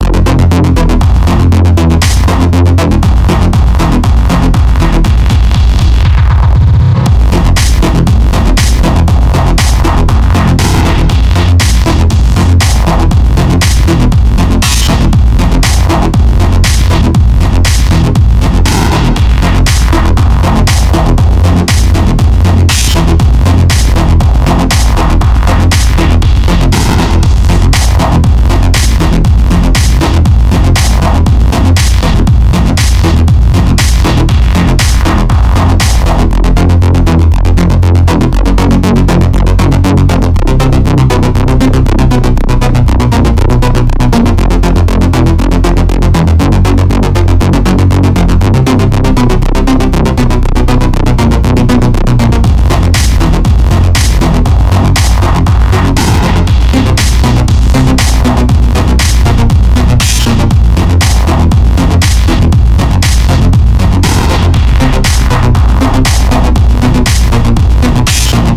Work in progress
Music / Techno
fat bass kick house
I guess the Glitches do kind of sound like 8bit fire.